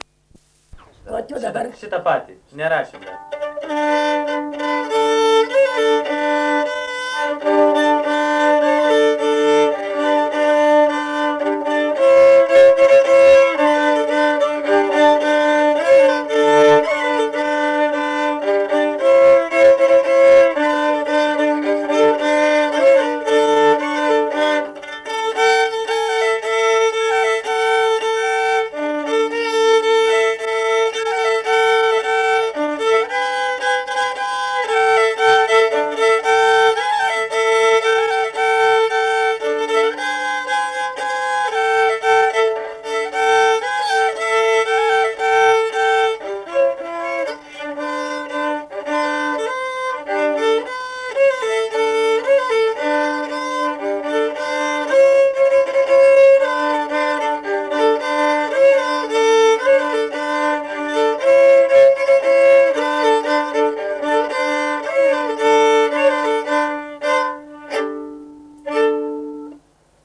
Subject šokis
Erdvinė aprėptis Palėvenėlė
Atlikimo pubūdis instrumentinis
Instrumentas armonika